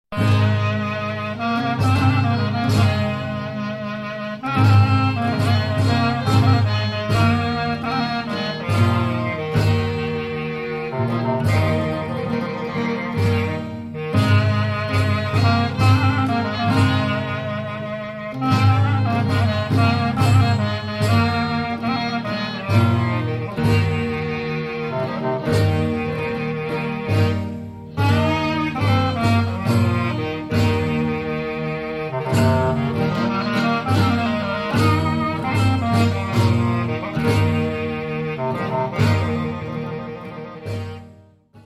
slow, heavy, men's dance from Epiros in 8/4 meter.